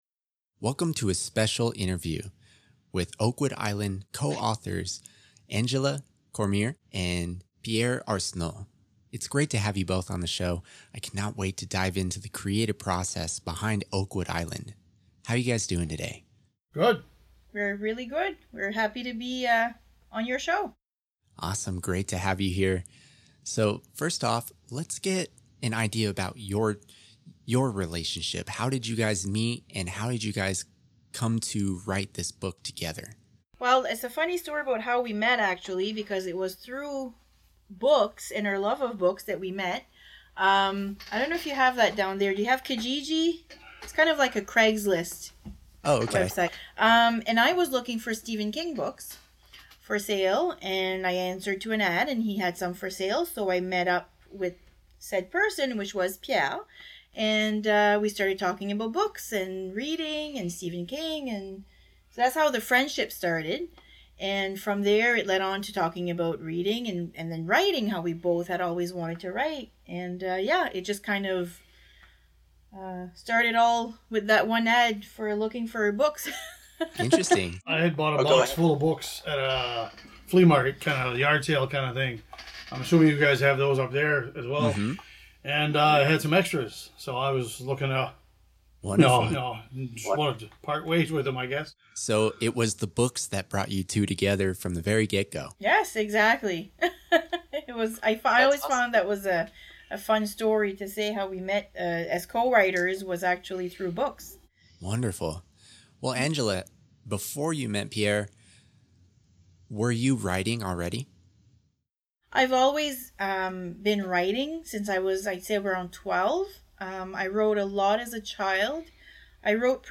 An audio interview
OAKWOOD ISLAND INTERVIEW.mp3